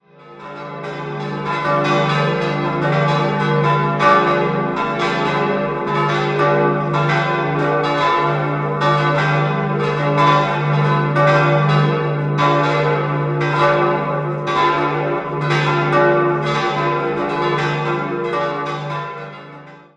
5-stimmiges ausgefülltes und erweitertes D-Moll-Geläute: d'-f'-g'-a'-c''
Josefsglocke a' 360 kg 1947/48* Karl Hamm, Regensburg
Schutzengelglocke c'' 220 kg 1947/48* Karl Hamm, Regensburg
bell